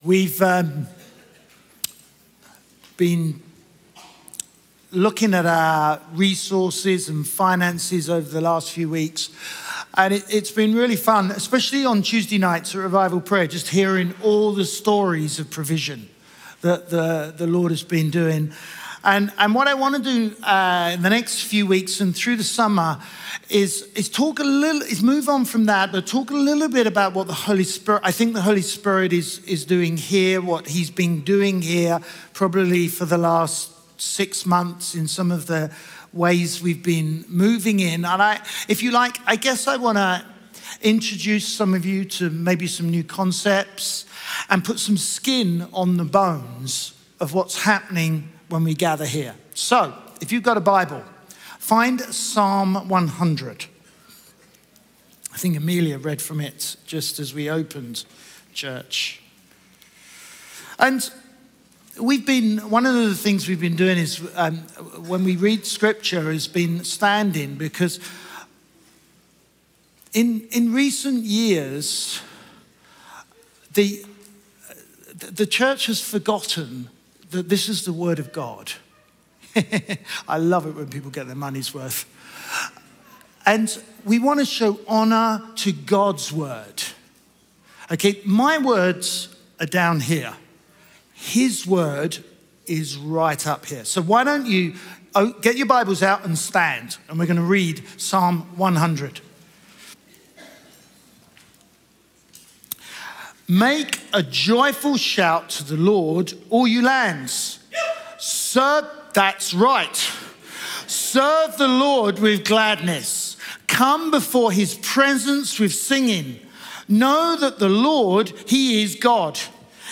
Chroma Church - Sunday Sermon Dance Like David Aug 23 2023 | 00:30:38 1x 00:00 / 00:30:38 Subscribe Share RSS Feed Share Link Embed